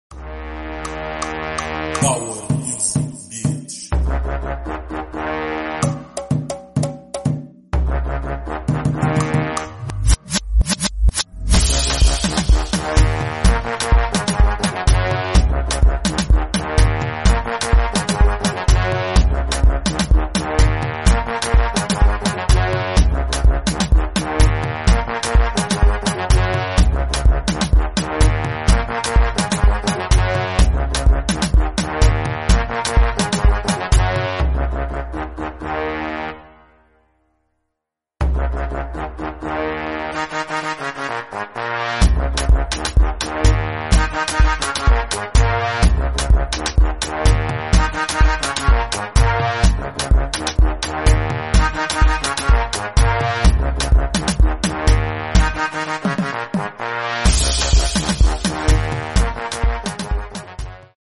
Let the trumpets wake your soul